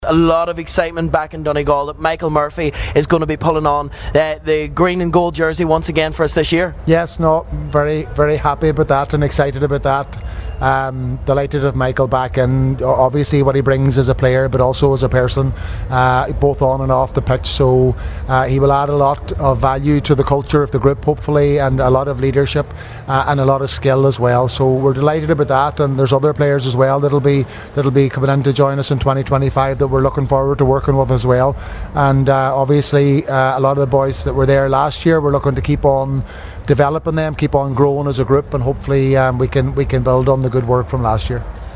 Donegal manager Jim McGuinness speaking
at last night’s event in London
Donegal manager Jim McGuinness was the guest speaker at last night’s Donegal Association Dinner Dance in London.